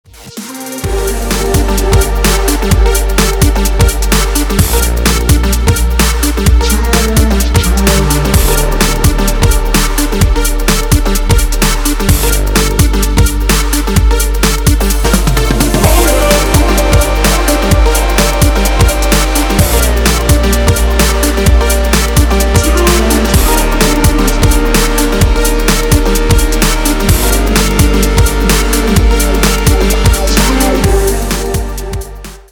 • Качество: 320, Stereo
мужской голос
breakbeats
Стиль: Breaks